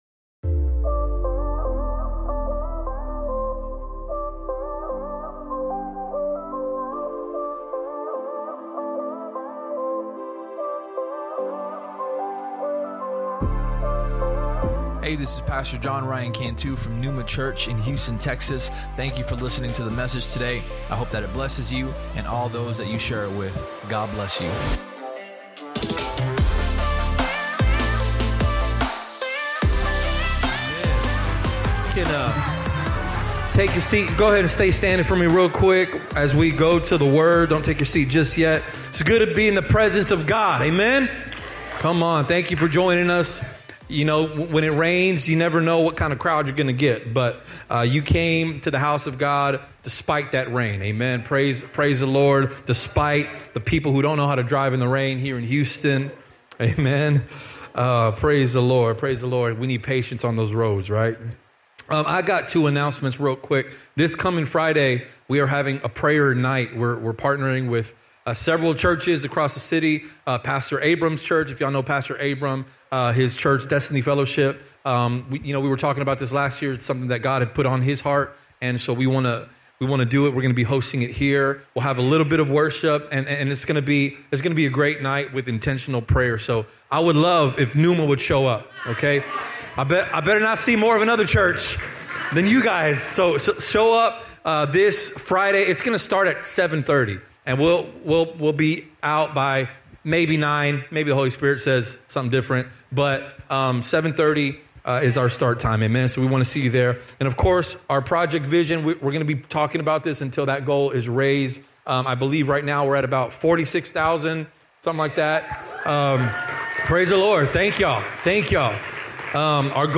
Sermon Topics: Spirits, Antichrist, Discernment Related Sermon: It’s Spiritual If you enjoyed the podcast, please subscribe and share it with your friends on social media.